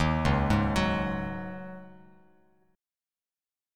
Dbsus2sus4 chord